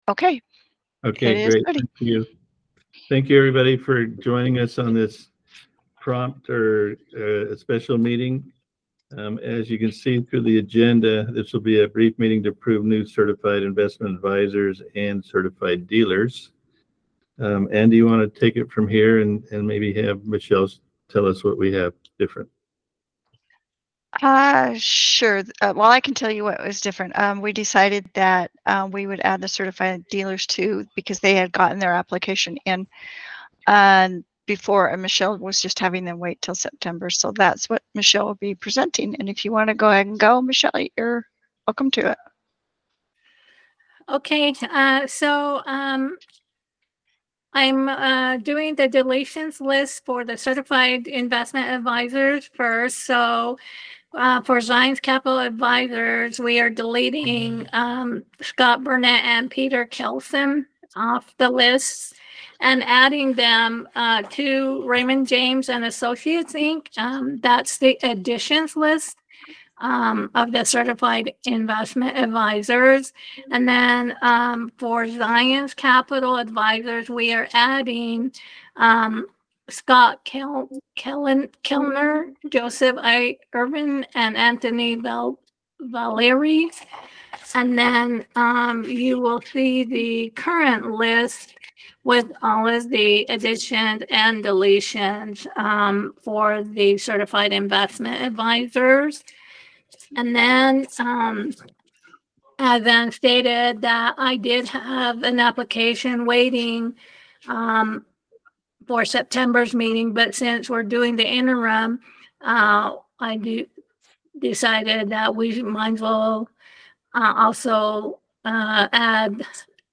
All Members of the Authority will participate via telephonic conference originated by the Chair, and the meeting shall be an electronic meeting,